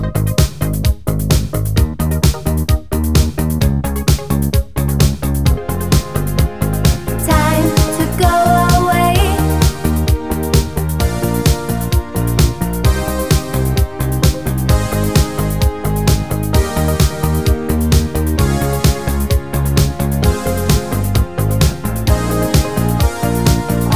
One Semitone Down Pop (1980s) 3:56 Buy £1.50